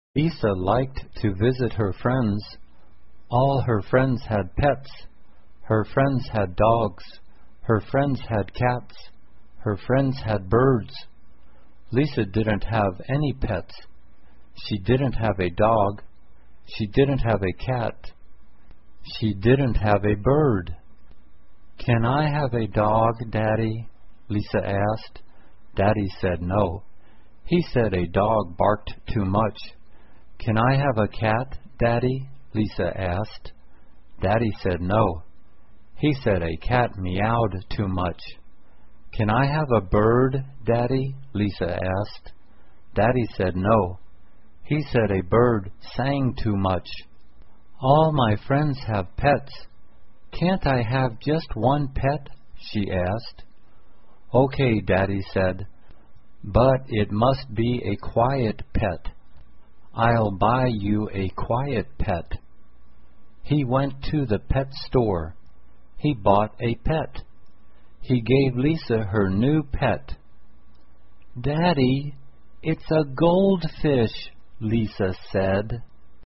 慢速英语短文听力 安静的宠物 听力文件下载—在线英语听力室